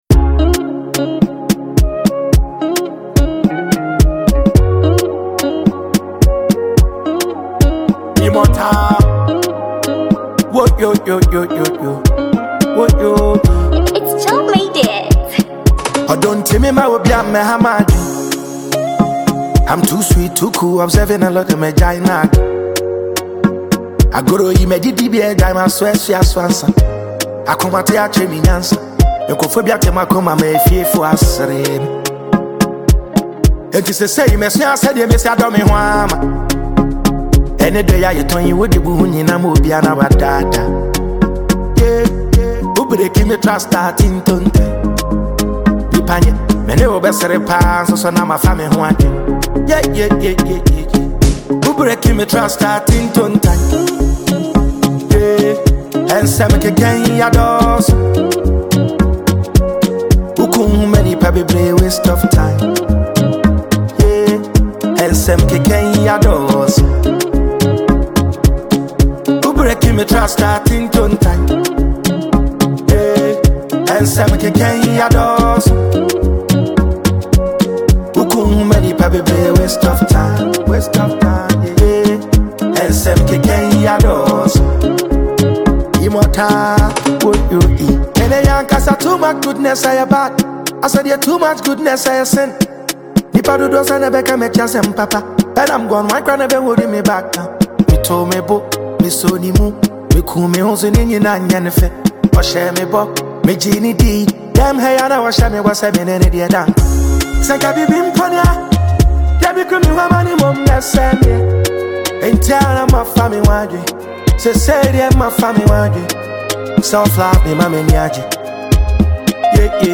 Ghanaian rap Sensation